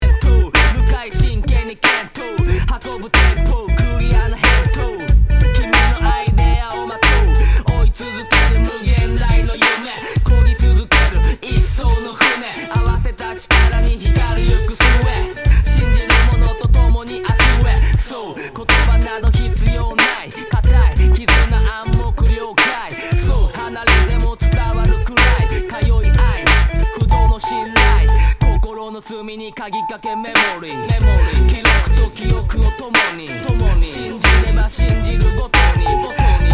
Tag       HIP HOP Japan